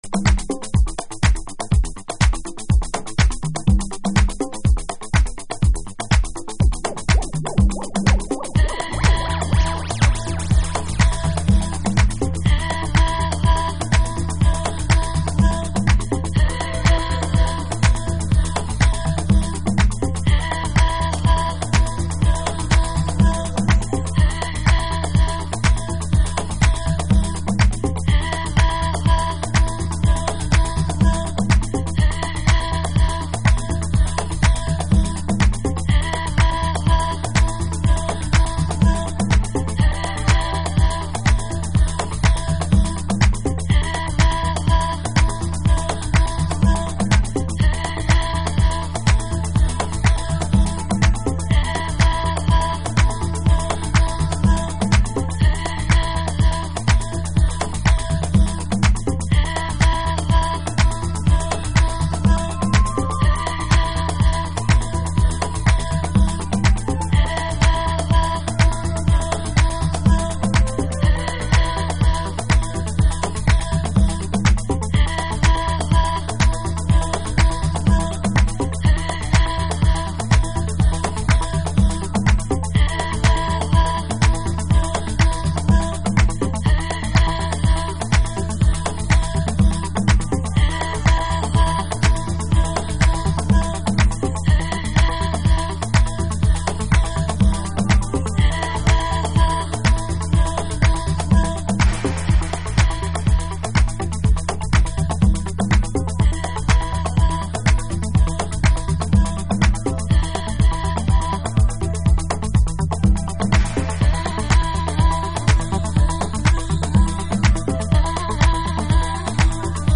極上のベースラインでグルーヴキープしてくれます。
House / Techno